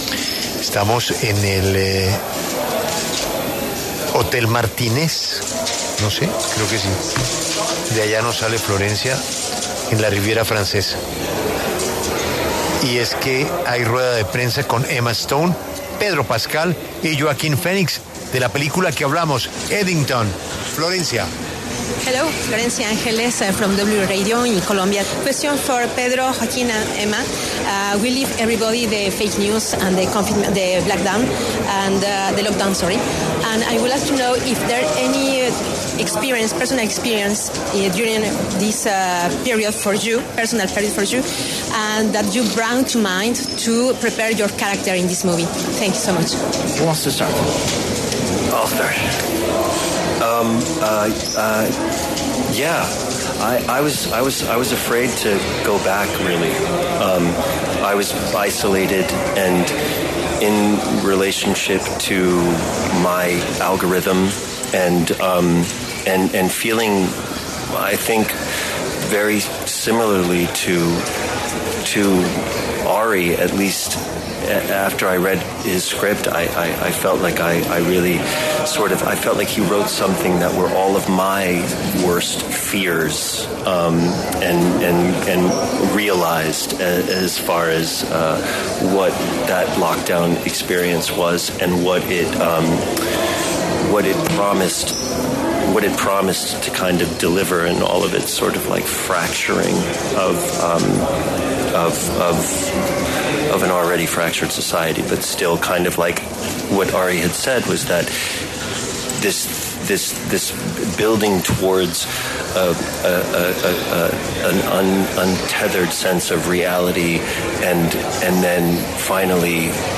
Phoenix, Pascal y Stone pasaron por los micrófonos de La W y detallaron que el confinamiento que se vivió a nivel mundial por el COVID-19 los ayudó a prepararse para interpretar cada uno de sus personajes.